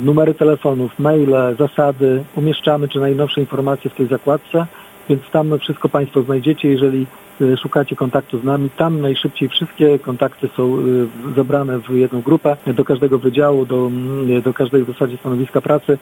Mówi Karol Iwaszkiewicz, burmistrz Giżycka: